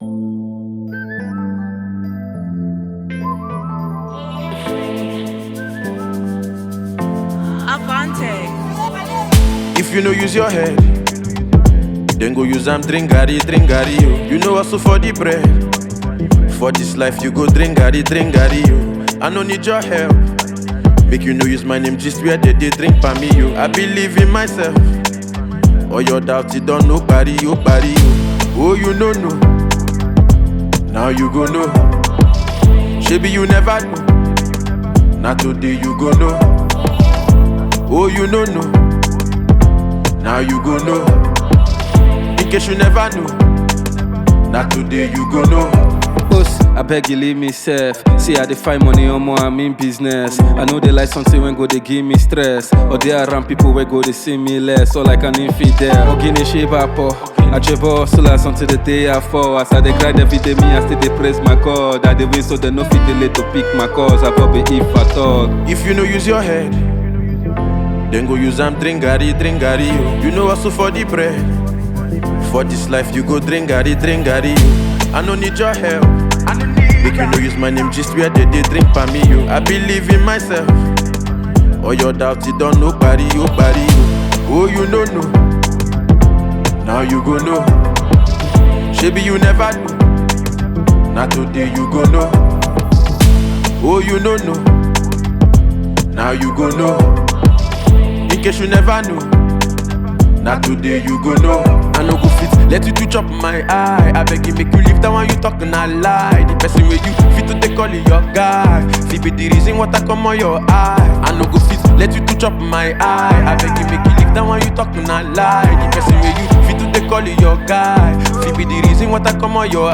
Nigerian musical duo
distinctive fusion of Afrobeats and pop music